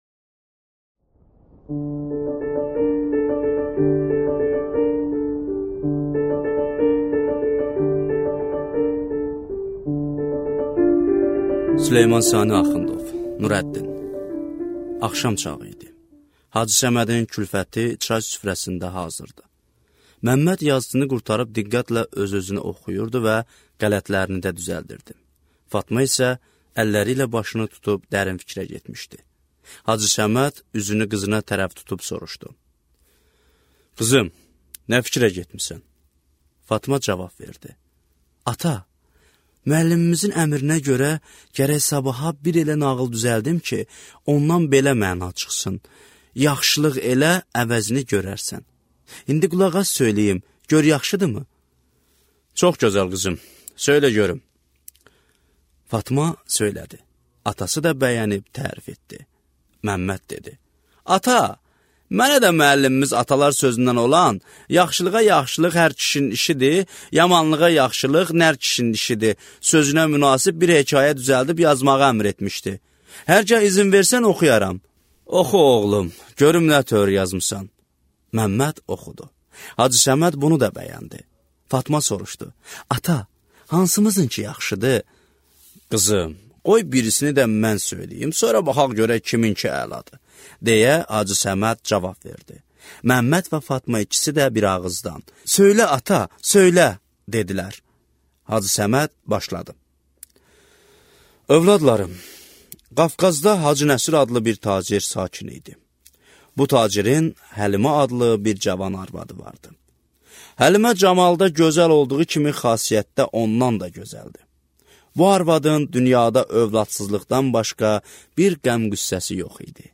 Аудиокнига Nurəddin | Библиотека аудиокниг